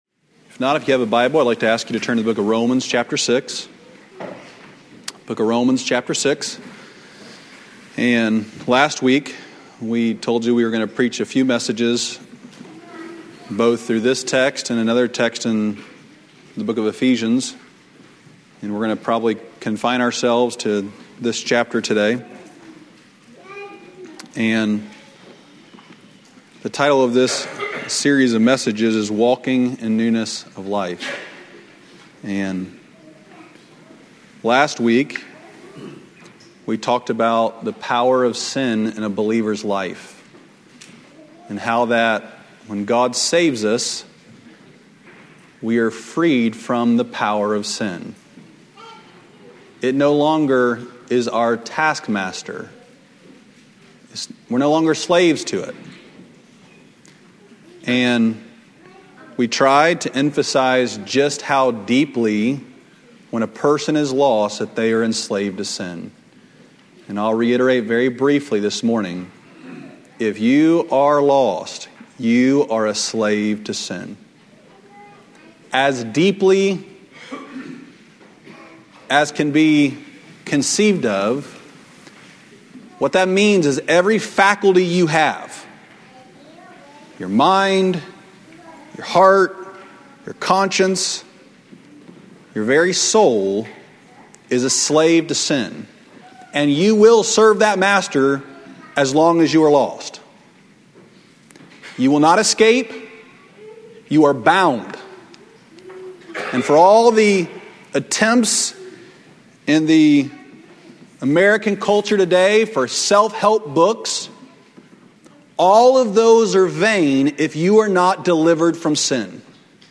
Sermons from our Sunday morning worship services.